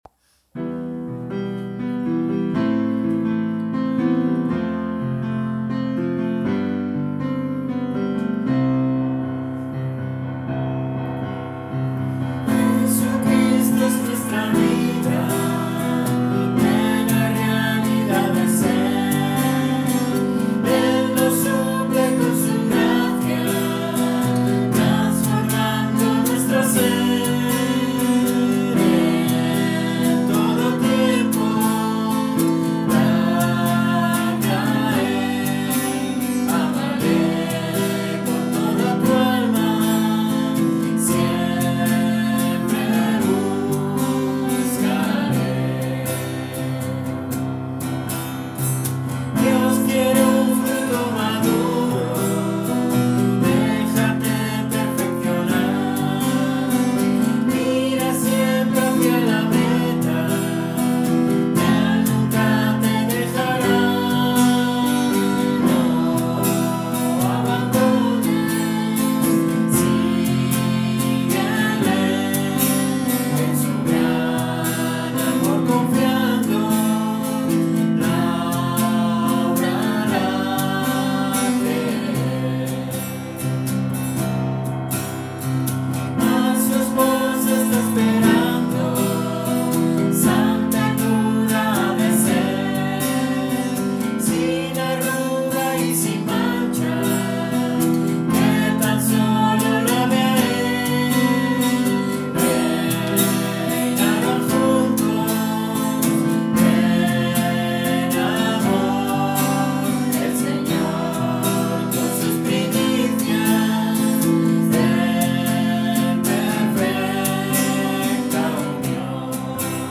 guitarra y piano